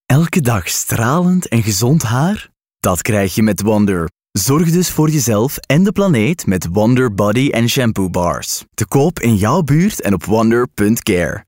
Commercial, Young, Urban, Versatile, Friendly
Commercial